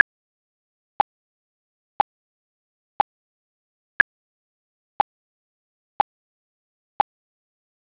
Here is a technically perfect 16 bit 8000 Hz mono WAV (Microsoft PCM) file. It is a metronome click at one click per second for 8 seconds.